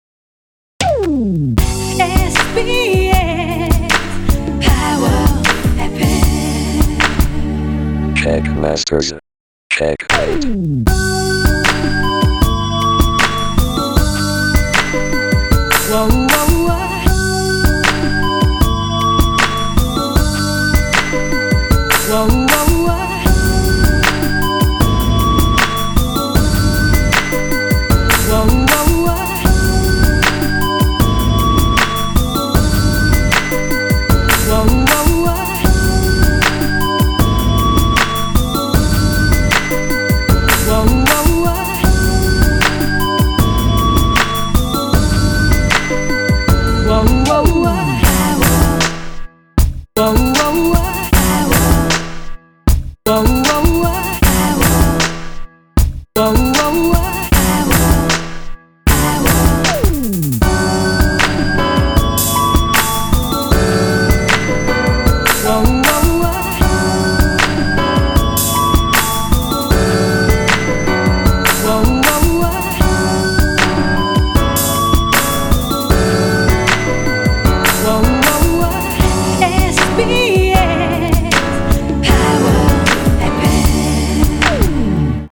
난 이걸 샘플링하겠다라고 마음 먹었었는데 ㅋ